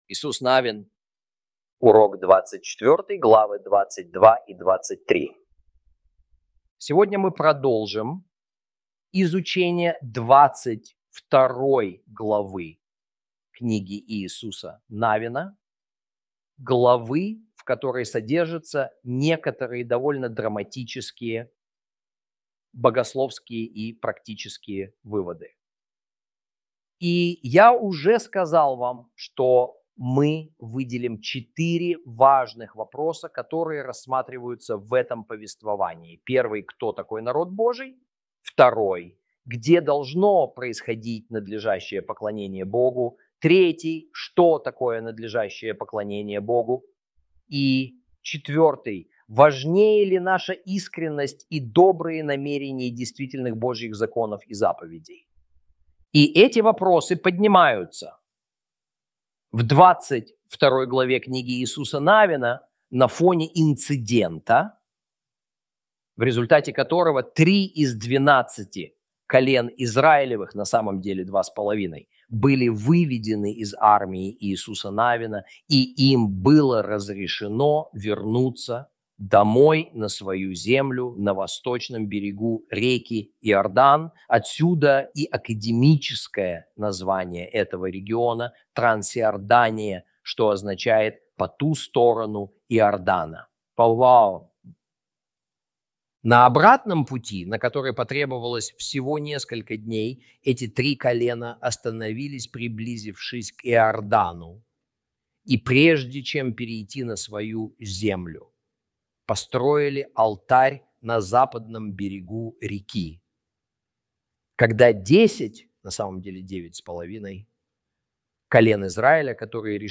Урок 24 - Кни́га Иису́са Нави́на Ch 22-23 - Torah Class